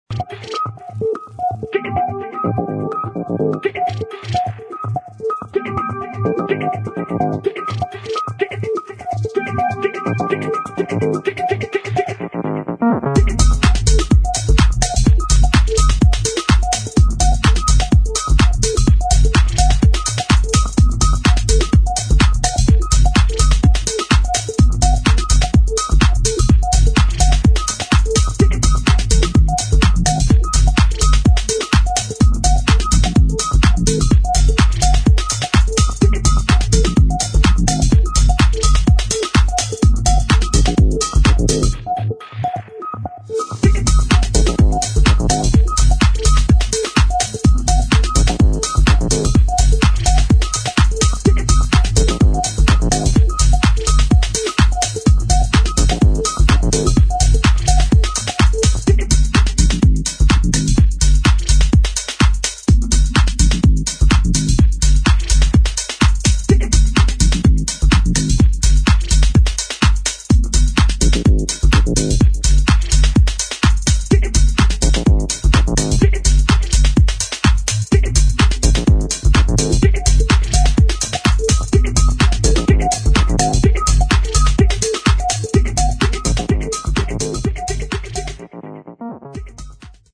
[ HOUSE ]